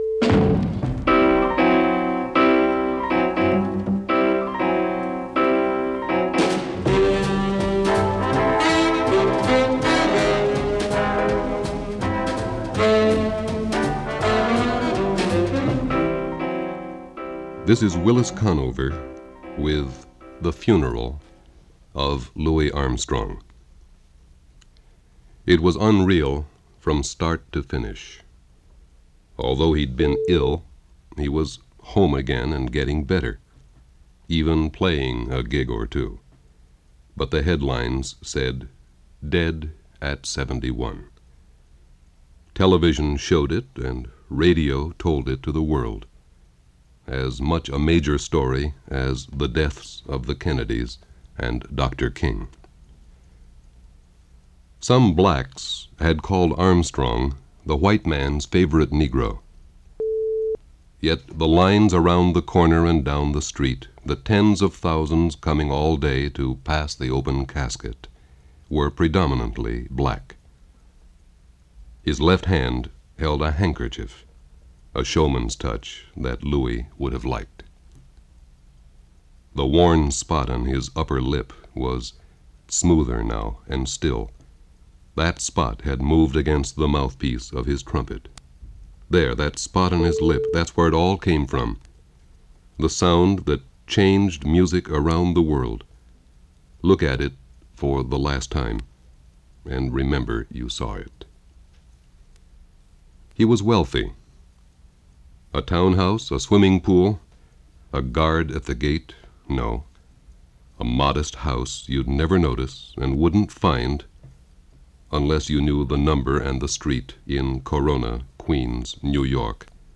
Voice-of-America-Funeral.mp3